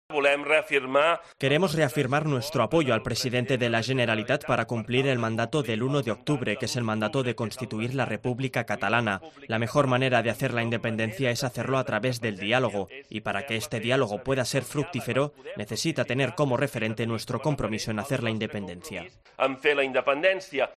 Junqueras ha realizado esta apelación en su discurso de apertura de la reunión del Consell Nacional de ERC, a la que asisten a ella 220 consejeros nacionales de unos 270 que hay en total.